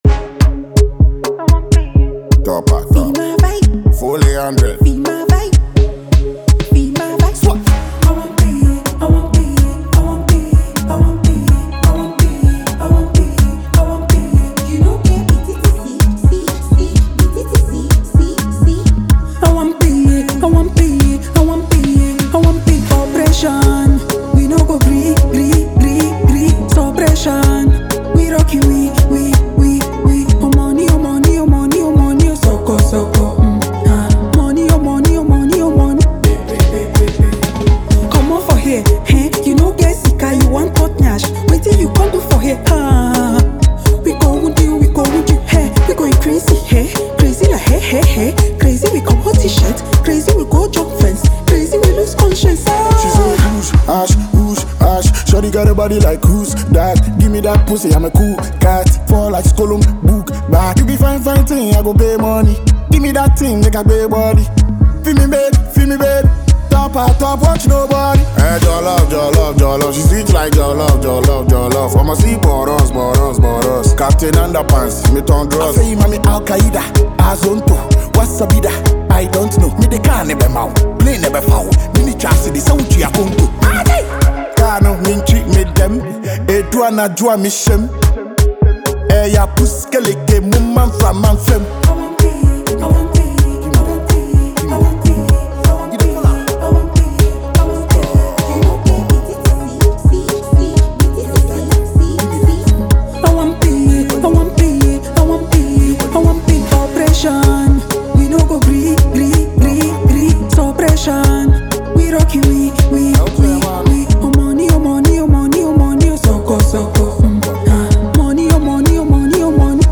Ghana MusicMusic
Fast-rising Ghanaian female singer
award-winning rapper